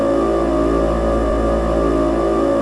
game_engine.wav